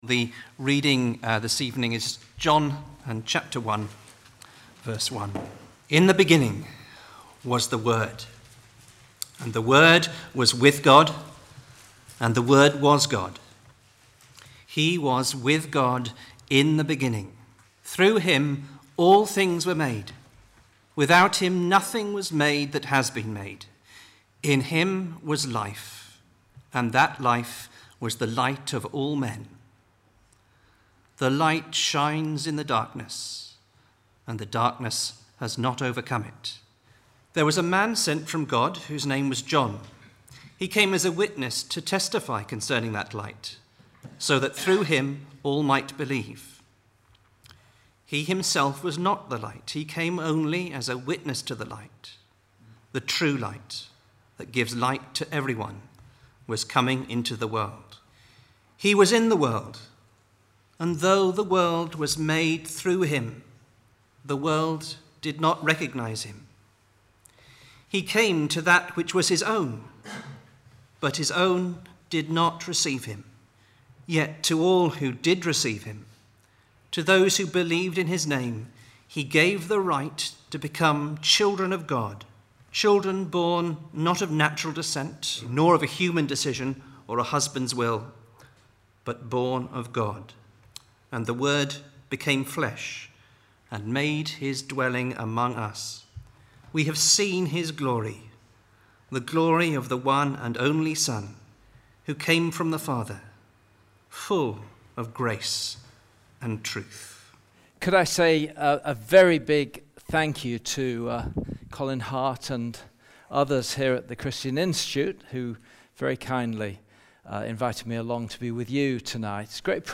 2016 Autumn Lectures